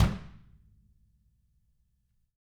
Index of /90_sSampleCDs/ILIO - Double Platinum Drums 1/CD2/Partition A/REMO KICK R